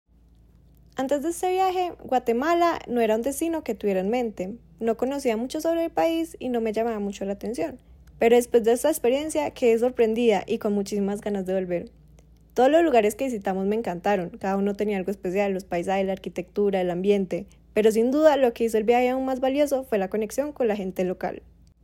Viajera del programa pioneros